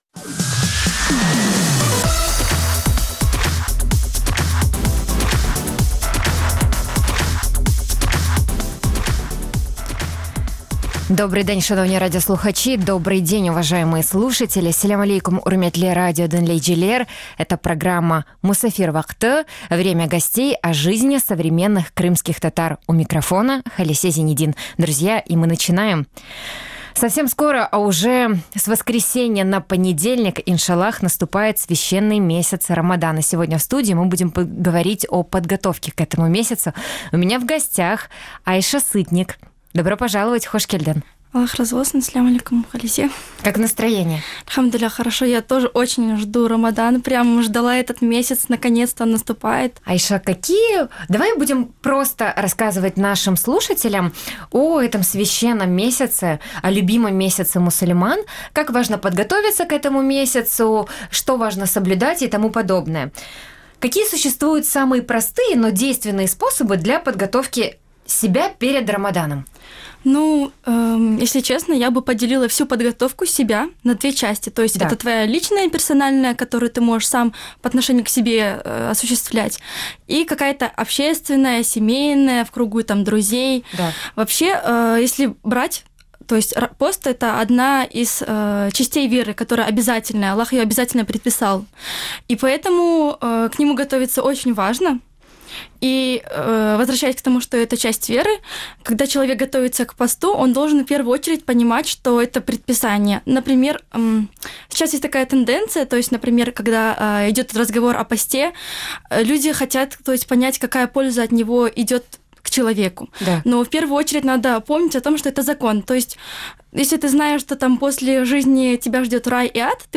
Эфир можно слушать Крыму в эфире Радио Крым.Реалии (105.9 FM), а также на сайте Крым.Реалии.